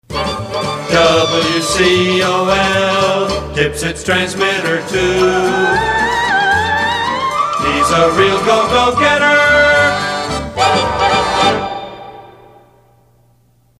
NOTE: These jingle samples are from my private collection.